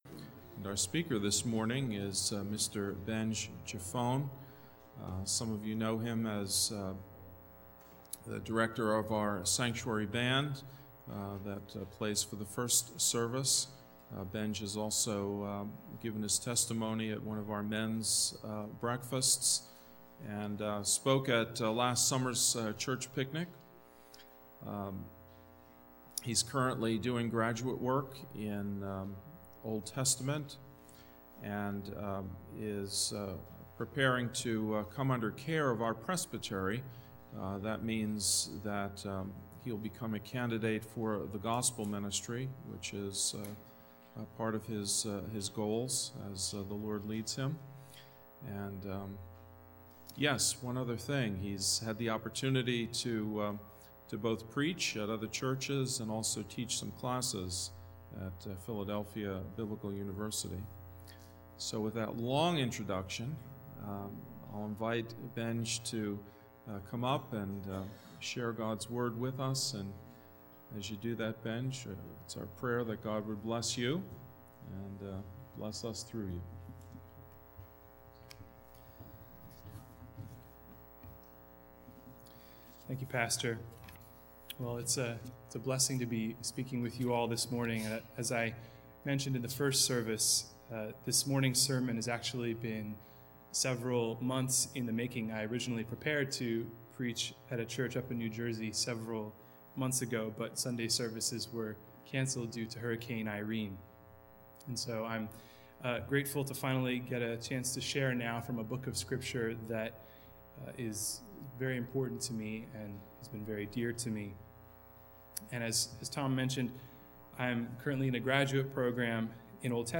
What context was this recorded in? A sermon at Lansdale Presbyterian Church...